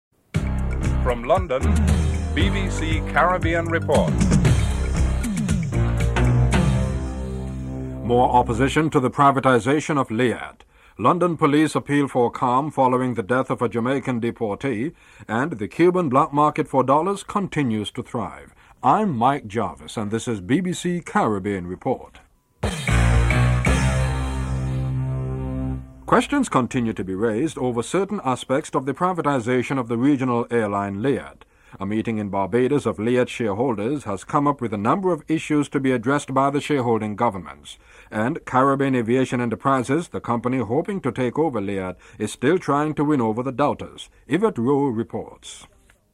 1. Headlines (00:00-00:31)
Interviews with Paul Condon, Metropolitan Police Commissioner and Bernie Grant, Labour MP (04:47-07:20)